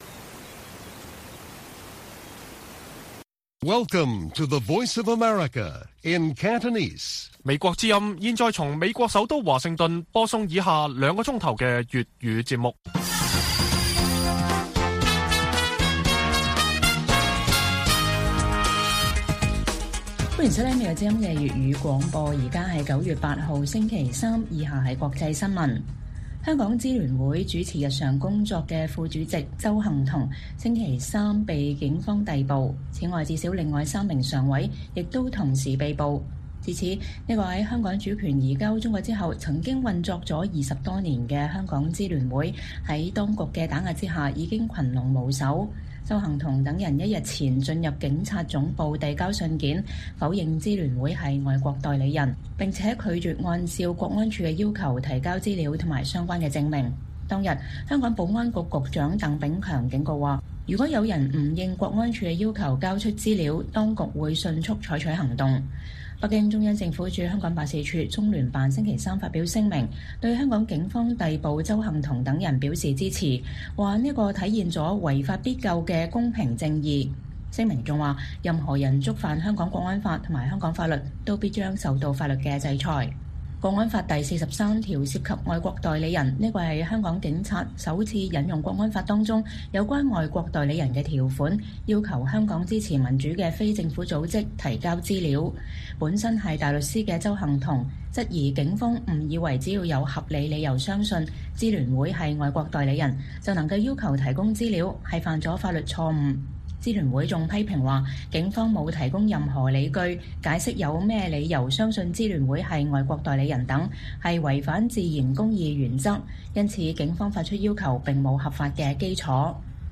粵語新聞 晚上9-10點: 多名香港支聯會成員因拒絕向國安處交資料而被捕